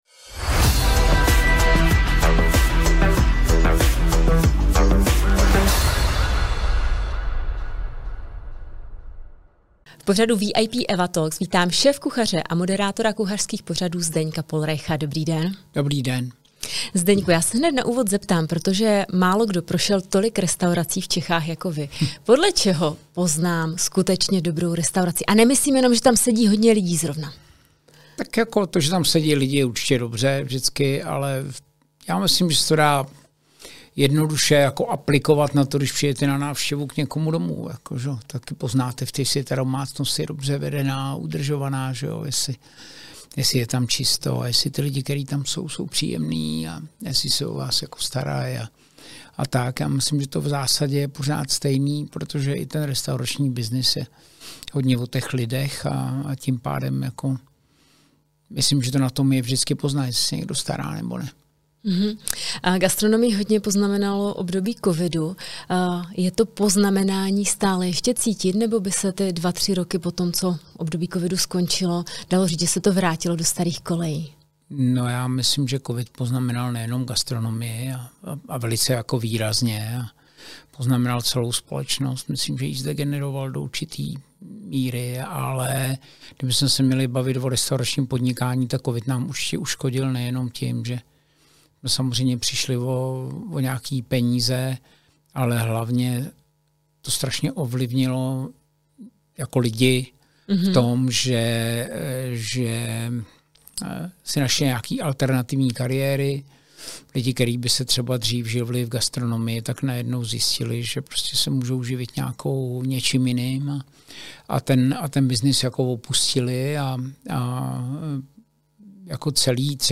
Pozvání do studia přijal jeden z nejznámějších kuchařů a moderátorů pořadů o gastronomii Zdeněk Pohlreich.